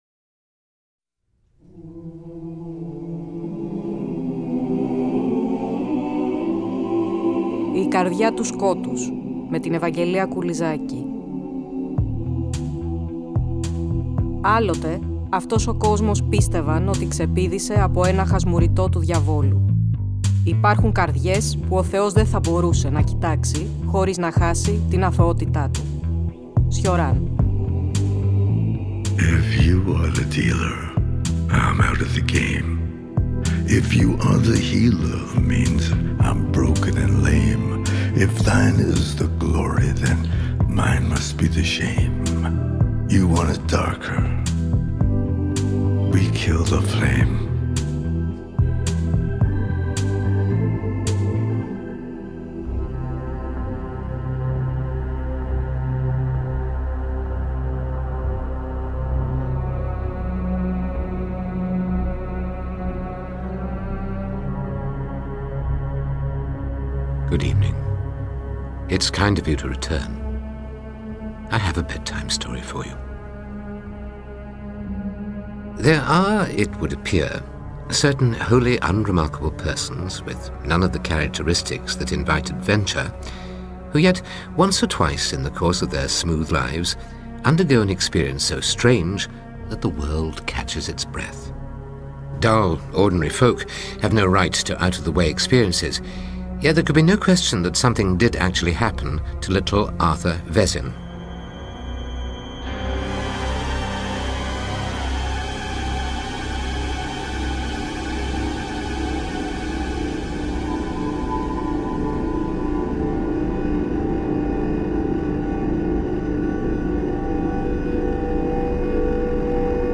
Μικρό bonus στην έναρξη η φωνή του Μπλάκγουντ , από ηχογράφηση του ίδιου να διαβάζει τις «Αρχαίες Μαγείες» στο ραδιόφωνο του BBC .